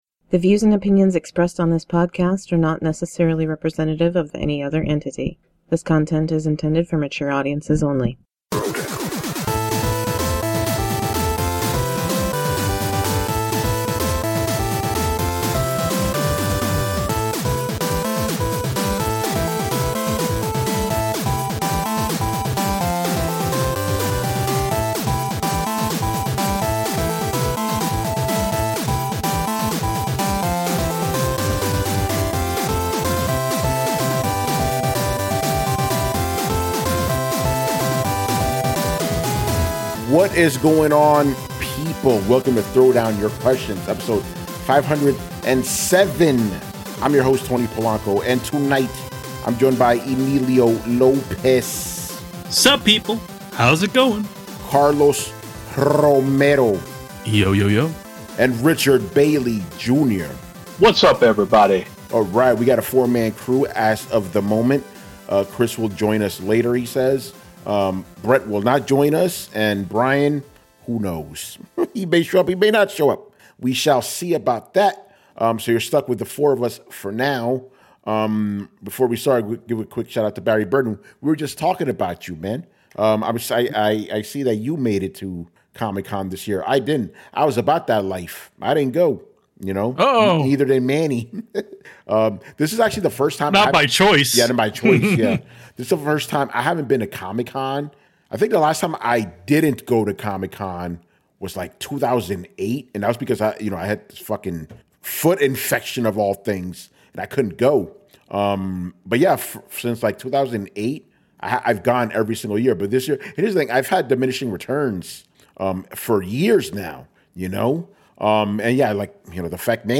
intro and outro music